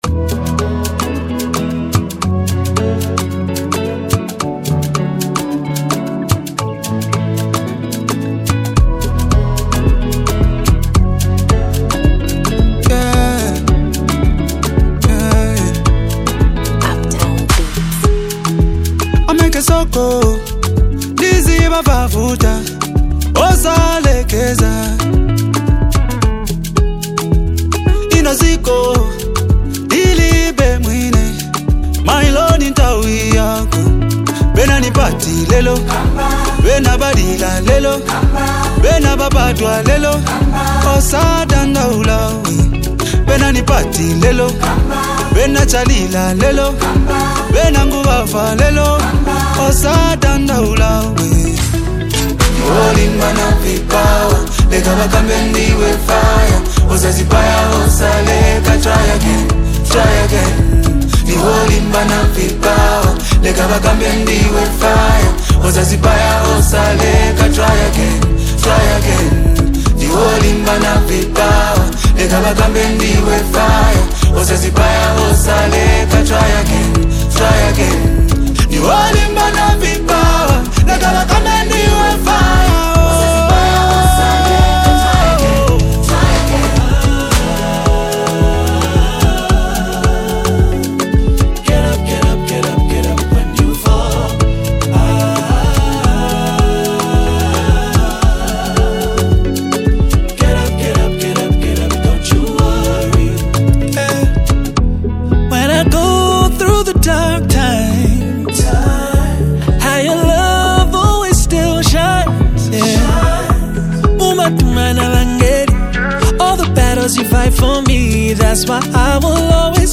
blending Afrobeat with gospel influences.